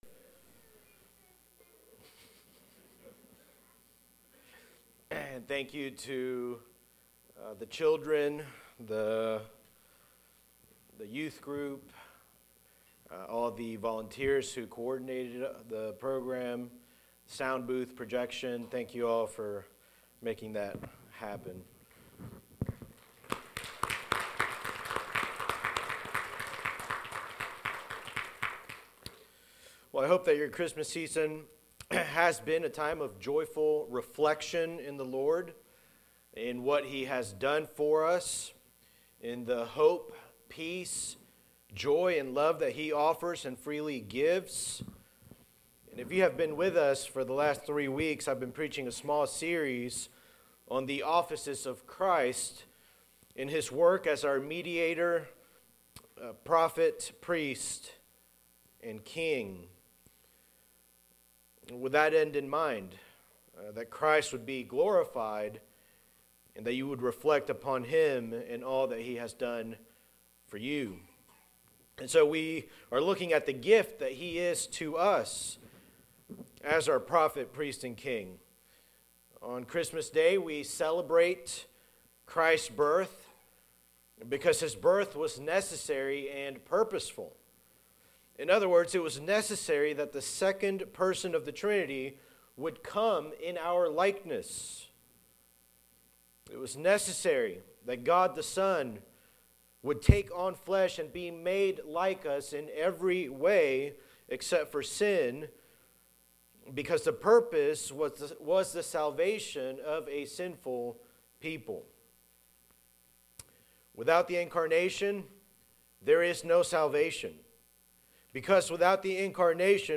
Sermons by Eatonville Baptist Church EBC